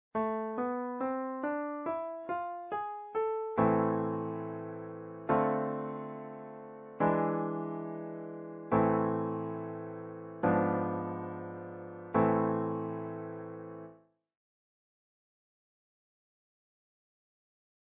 harmonic minor from A
G# diminished